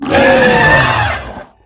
hurt1.ogg